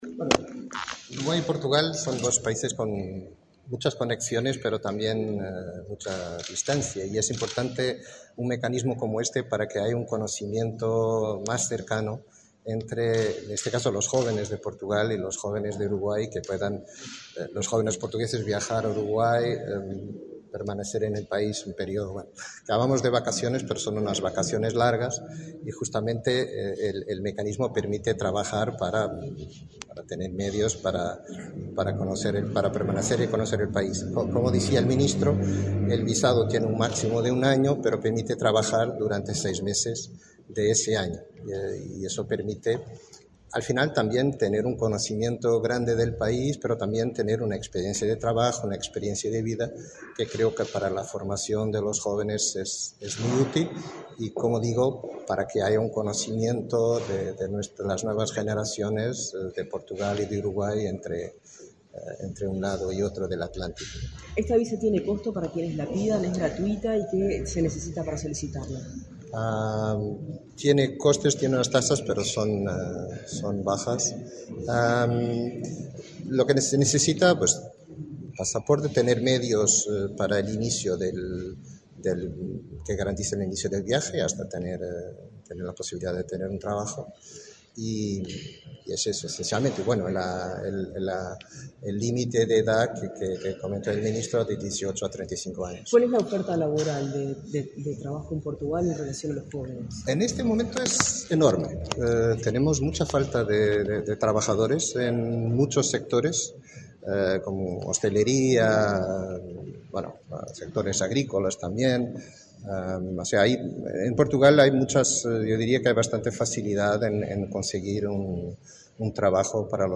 Declaraciones del embajador de Portugal, João Pedro Lourenço Antunes
Declaraciones del embajador de Portugal, João Pedro Lourenço Antunes 22/11/2024 Compartir Facebook X Copiar enlace WhatsApp LinkedIn Este viernes 22 en la sede de la Cancillería, el ministro Omar Paganini, firmó un acuerdo con el embajador de Portugal, João Pedro Lourenço Antunes, para desarrollar un programa de vacaciones y trabajo. Luego, el diplomático explicó a la prensa el alcance del convenio.